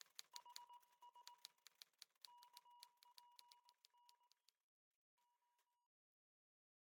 morsecode.ogg